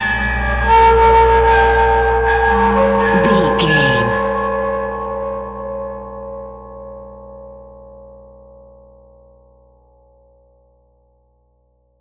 Atonal
scary
ominous
dark
suspense
haunting
eerie
creepy
synth
keyboards
ambience
pads
eletronic